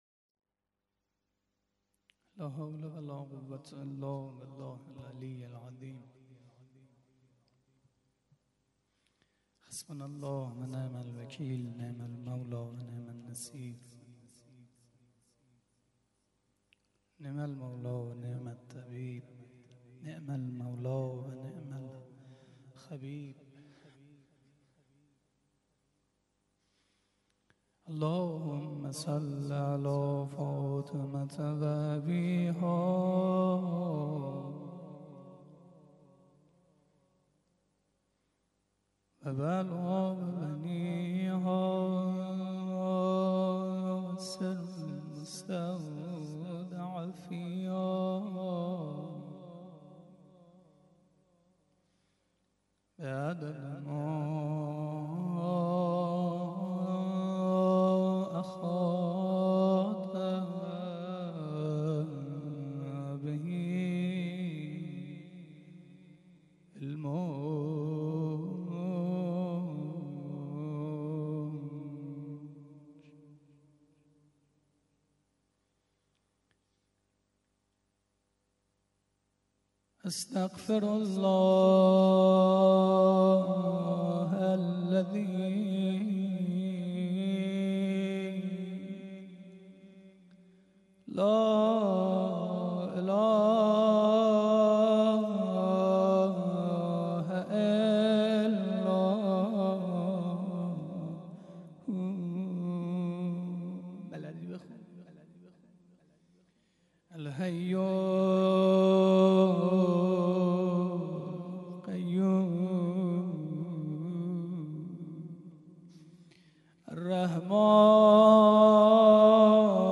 1روضه-تخریب-بقیع-اباالفضل.mp3